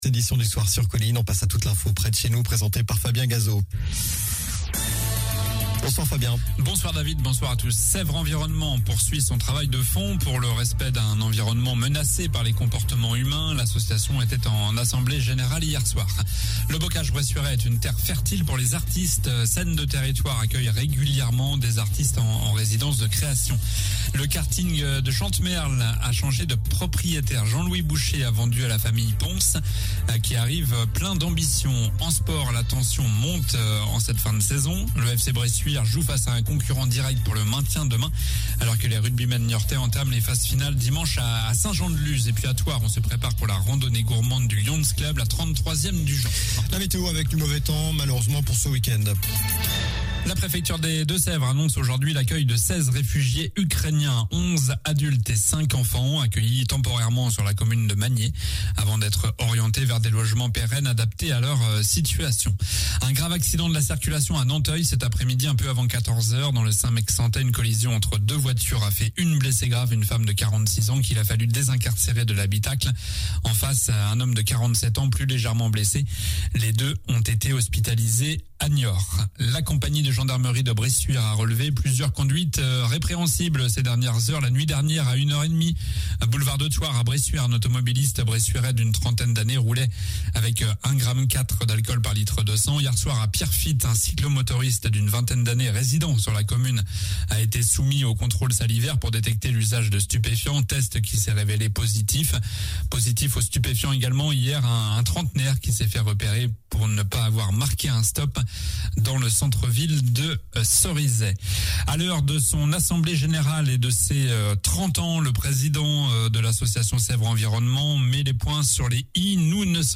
Journal du vendredi 22 avril (soir)